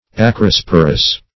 Acrosporous \Ac"ro*spor"ous\, a. Having acrospores.